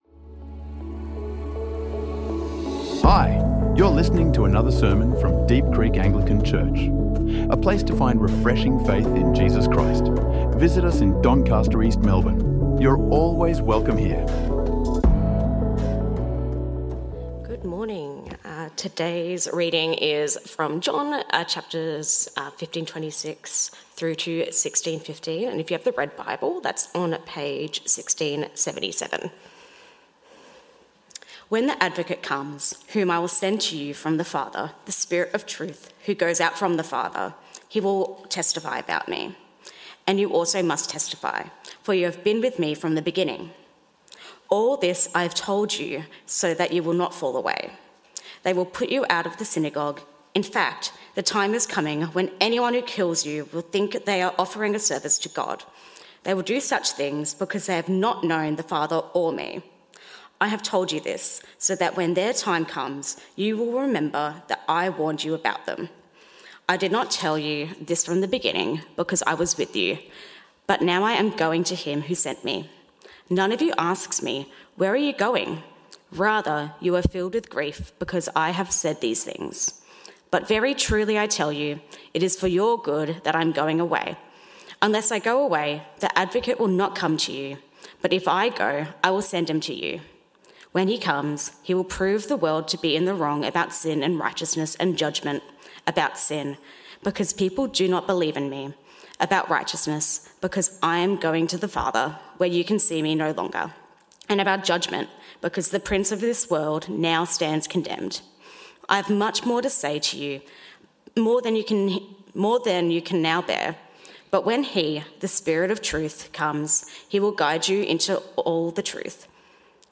Jesus Give Us His Spirit | Sermons | Deep Creek Anglican Church